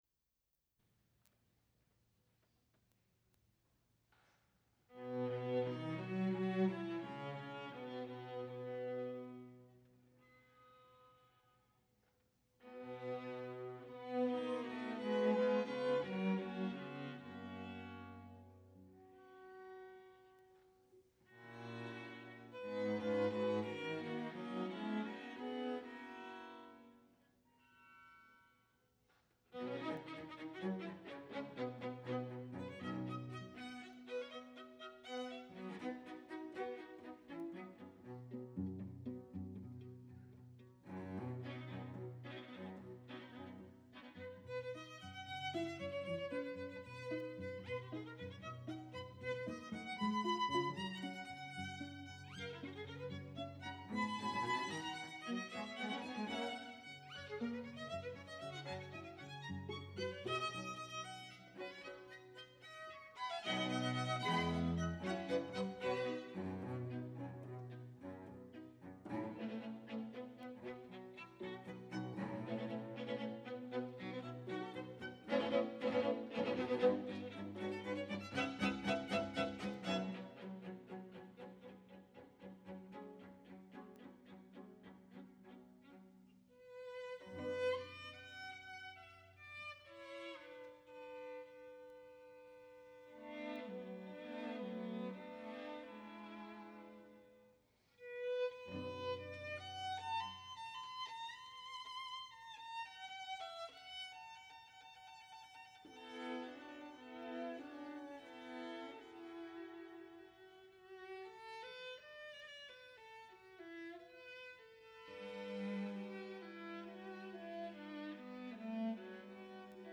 for String Quartet (1996, rev. 2003)